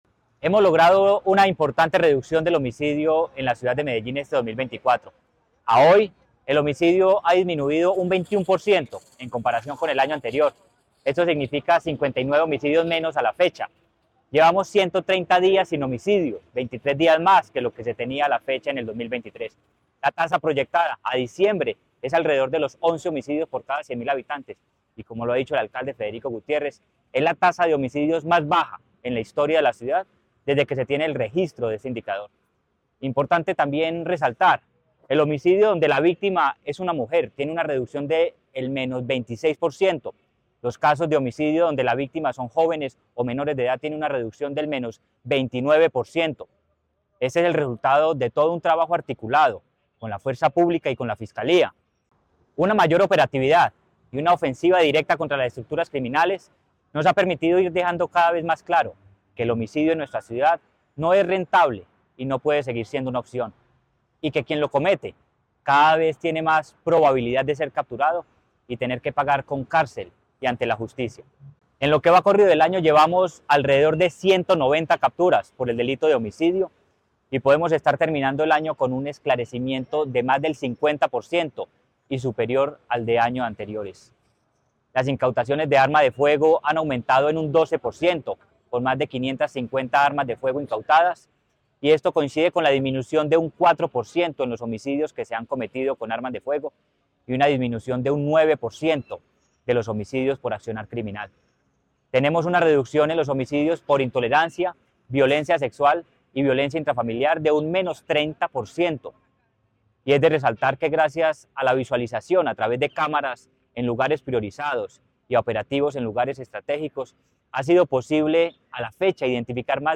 Palabras de Manuel Villa Mejía, secretario de Seguridad y Convivencia de Medellín Medellín sigue avanzando en la reducción de los homicidios con un 21 % en comparación con el año anterior.